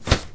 shield-hit-4.ogg